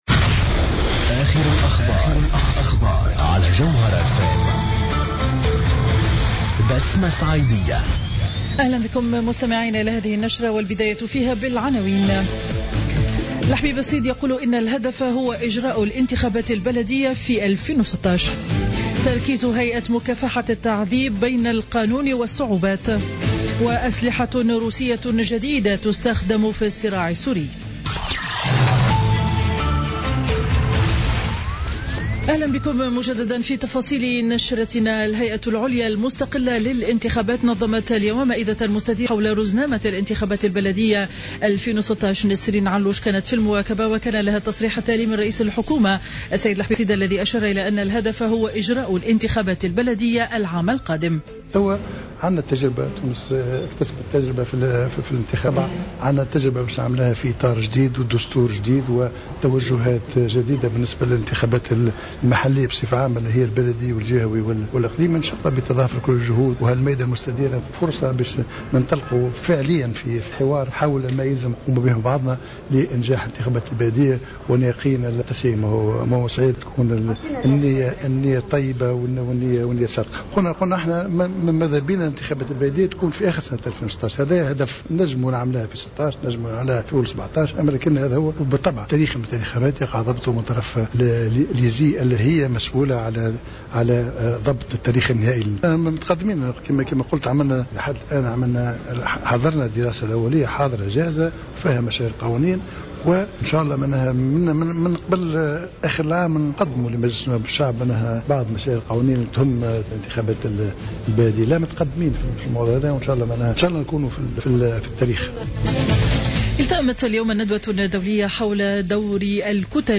نشرة أخبار منتصف النهار ليوم الخميس 17 سبتمبر2015